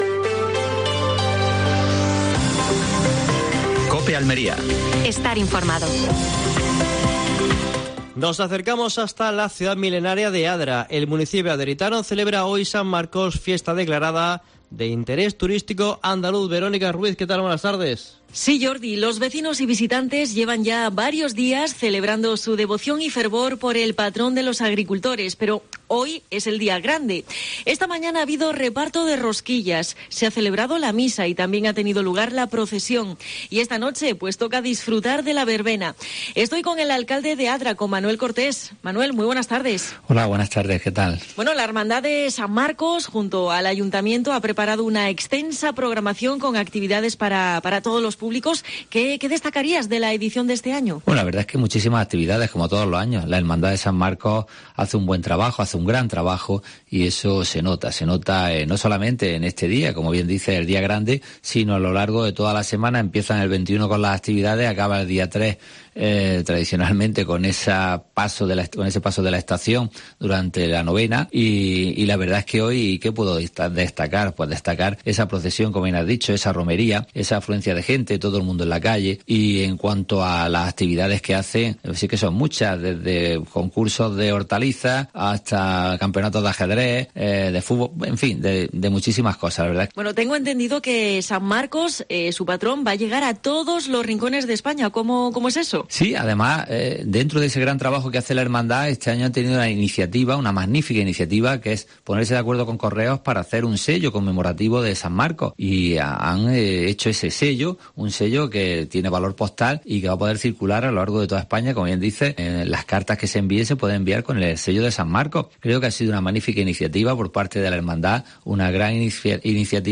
AUDIO: Última hora en Almería. Entrevista al alcalde de Adra (Manuel Cortés).